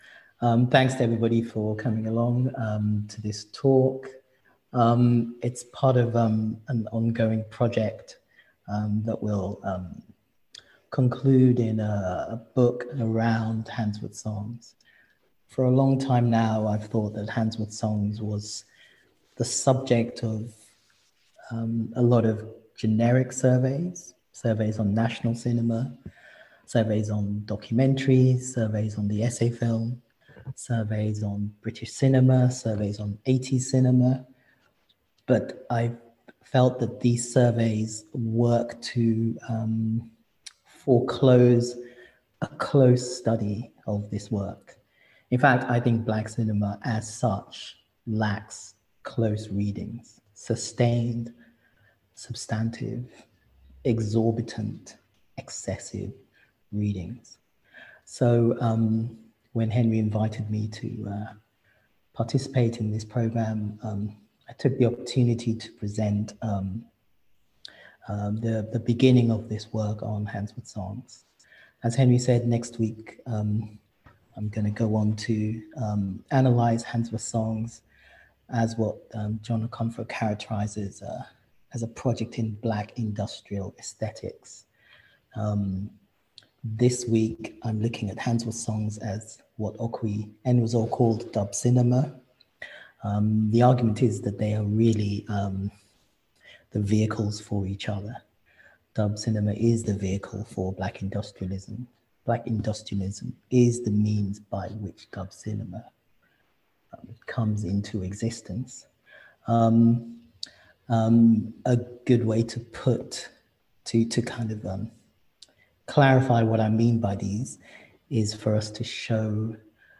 Lecture One expands on Enwezor’s formulation by treating dub cinema as an aesthetic methodology that explains the Black Audio Film Collective’s notion of black audio as an ecology for theorising what constitutes black filmand black collectivity within the informally segregated cine-culture of the British Left.
via Zoom